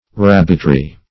rabbitry - definition of rabbitry - synonyms, pronunciation, spelling from Free Dictionary
Search Result for " rabbitry" : The Collaborative International Dictionary of English v.0.48: Rabbitry \Rab"bit*ry\ (r[a^]b"b[i^]t*r[y^]), n. A place where rabbits are kept; especially, a collection of hutches for tame rabbits.